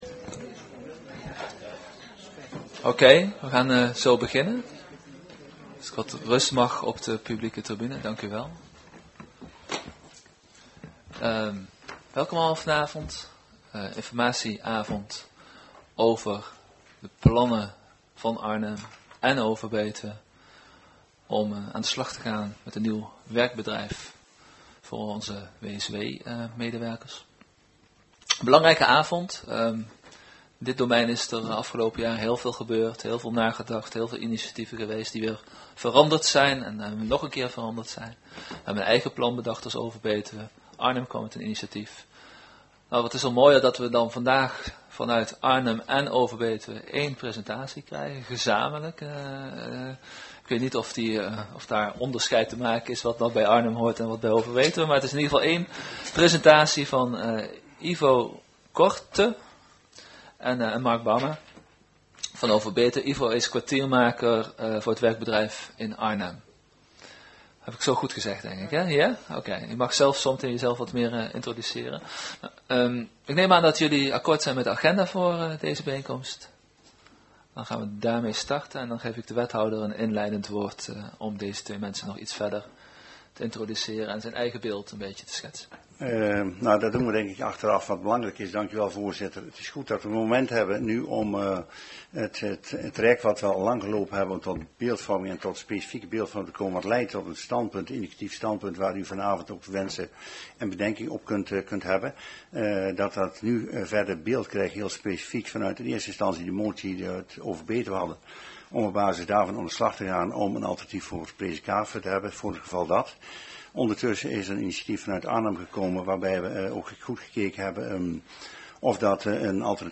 Commissiekamer, gemeentehuis Elst